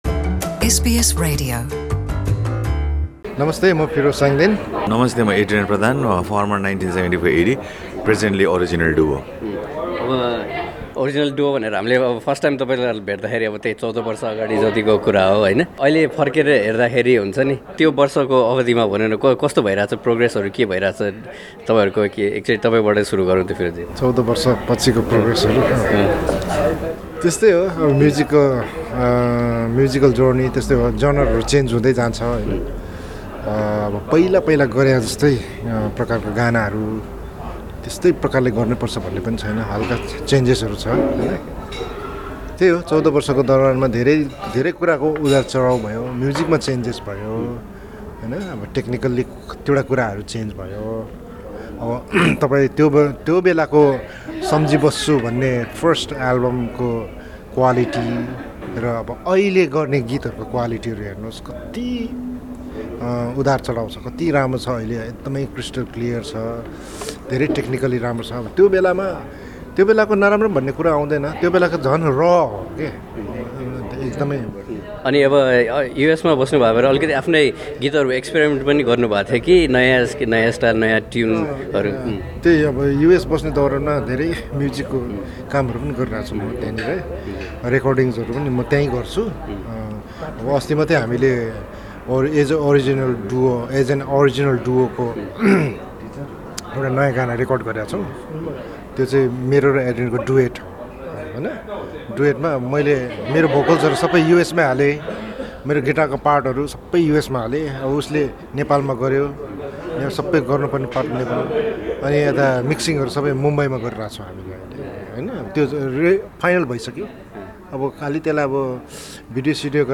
फिरोज स्यांगदेन र एड्रियन प्रधानले आफूहरूको साङ्गीतिक रूपान्तरण र भविष्यको योजनाबारे एसबीएस नेपालीसँग गरेको कुराकानी।